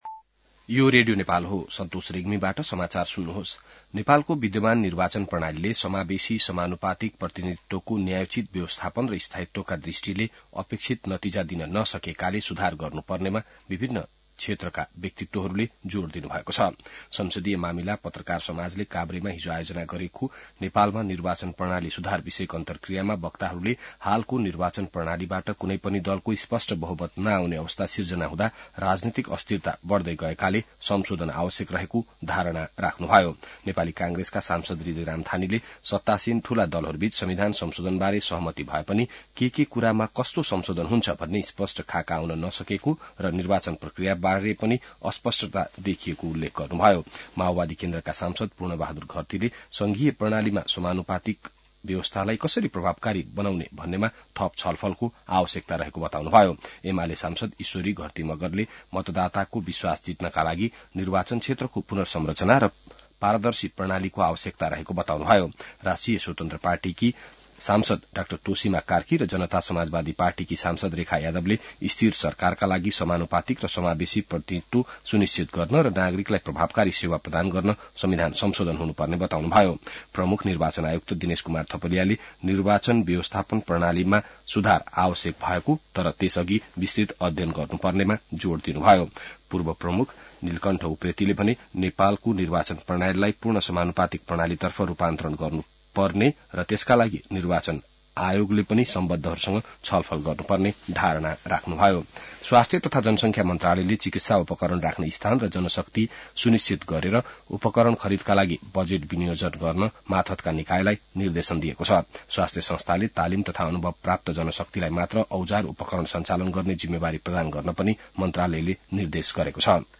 बिहान ६ बजेको नेपाली समाचार : ७ माघ , २०८१